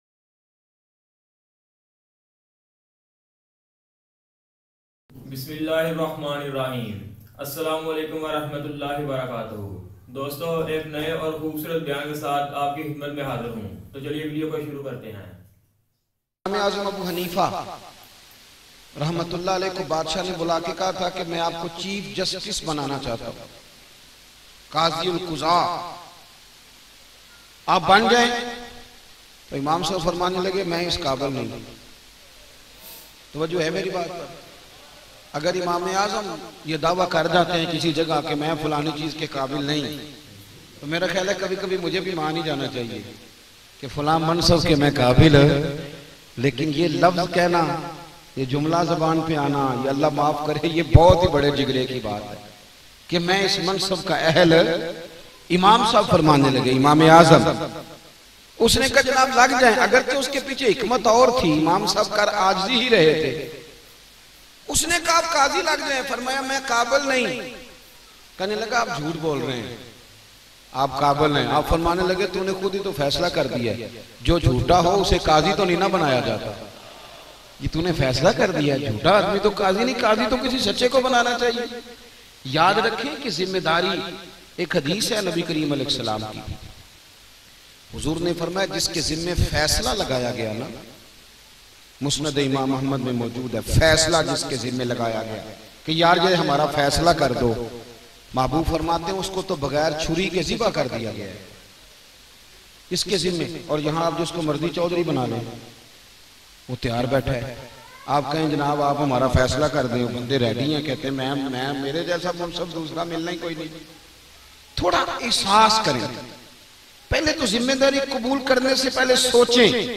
Imam Abu Hanifa Aur Waqat Ka Badsha Bayan